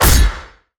timberchain_impact_damage.wav